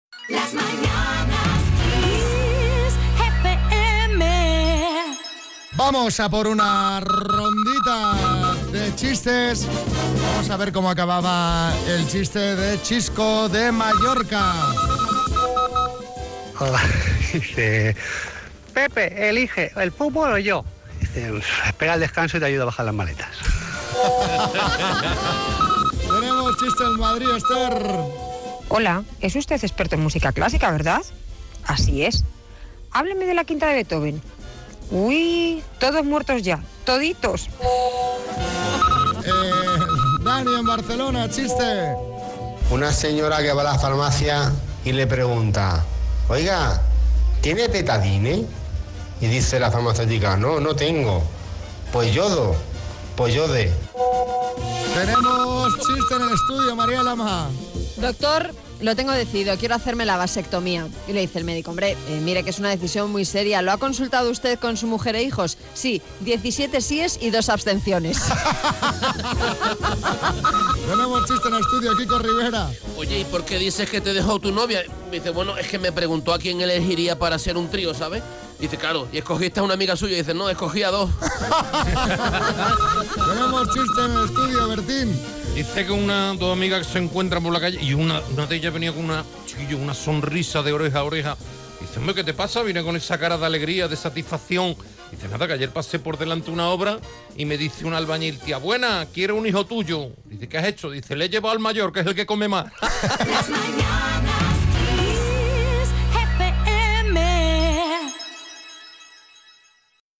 Nos vamos hasta Madrid, Barcelona y Mallorca para echarnos unas risas con los chistes de nuestros oyentes.